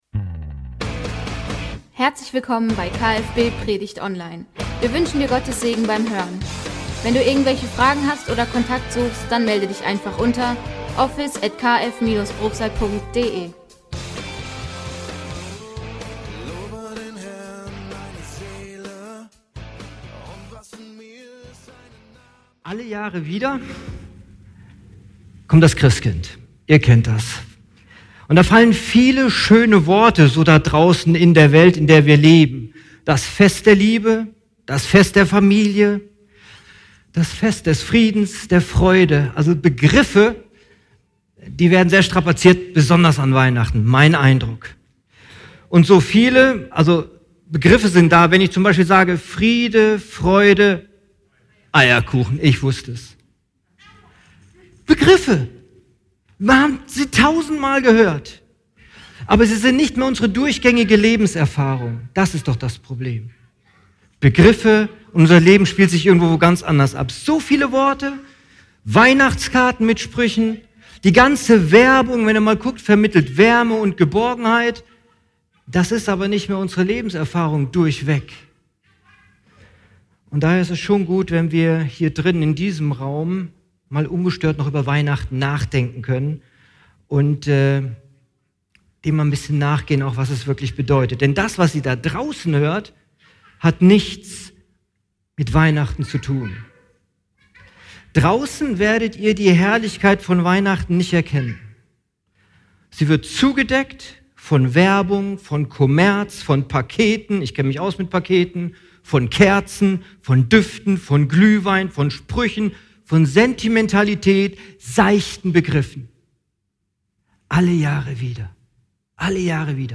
Heiligabendgottesdienst